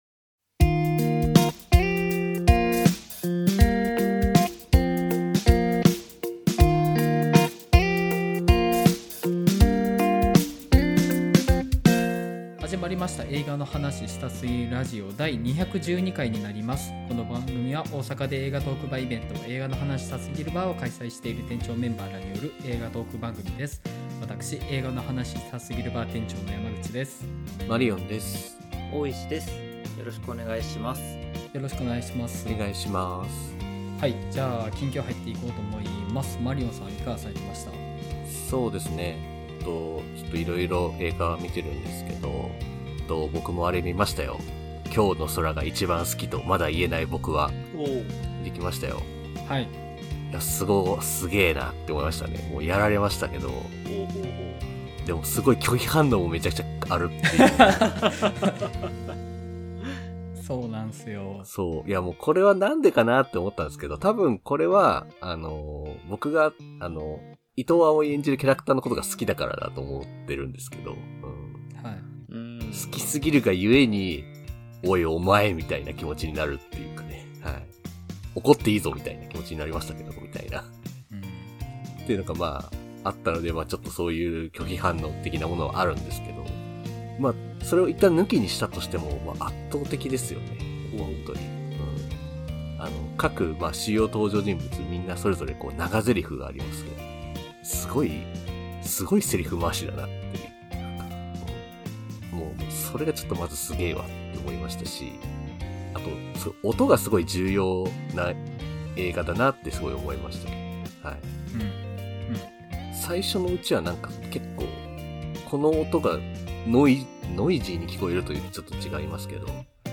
11/11、『すずめの戸締まり』公開日に鑑賞直後にTwitterスペースにて感想トークを配信しました。こちらの(ほぼ)ノーカットアーカイブとなります。